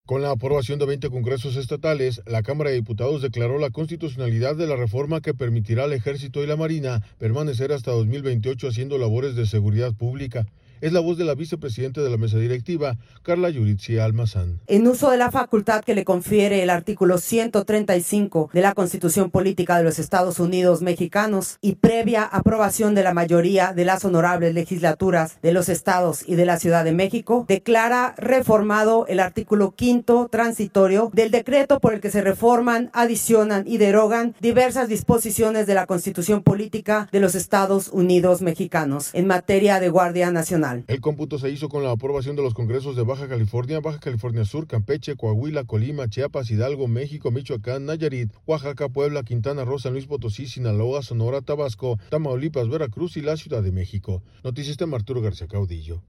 Con la aprobación de 20 congresos estatales, la Cámara de Diputados declaró la constitucionalidad de la reforma que permitirá a las Fuerzas Armadas permanecer hasta 2028 haciendo labores de seguridad pública. Es la voz de la vicepresidente de la Mesa Directiva, Karla Yuritzi Almazán.